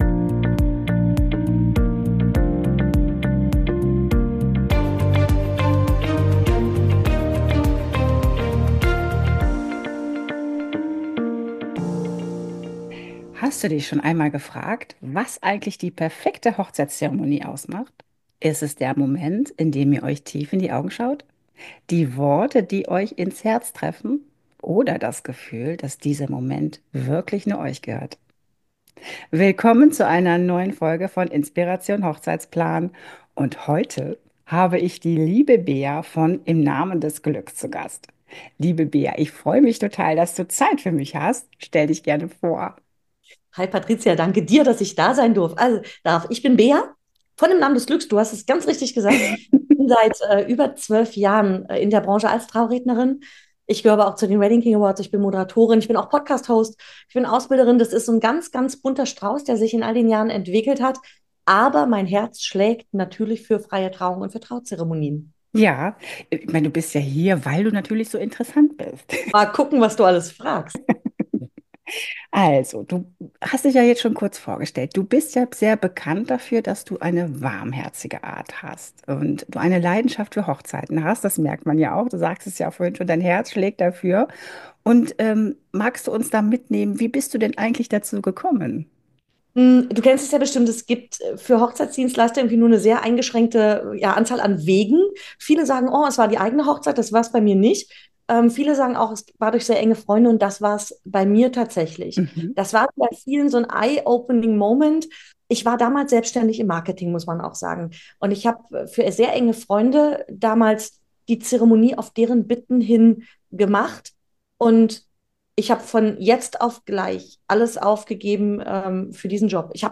Was macht eine freie Trauung eigentlich so besonders? Ein Gespräch